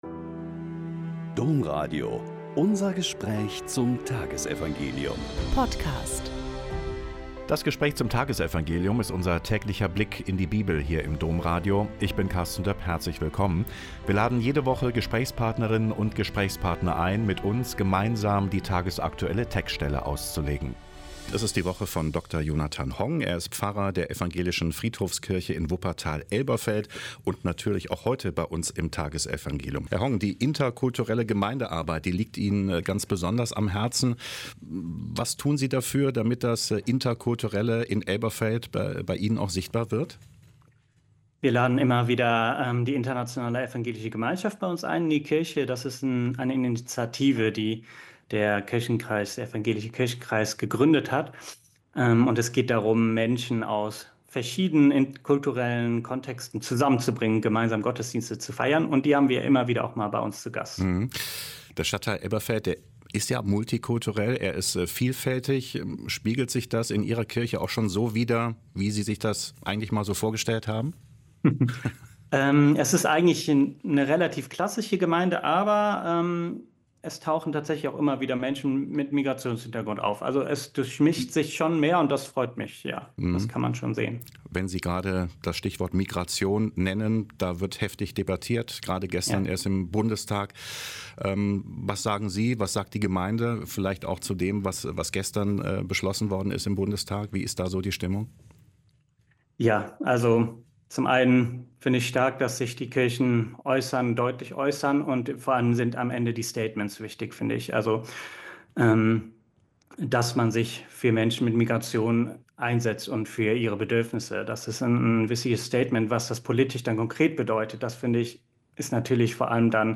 Mk 4,21-25 - Gespräch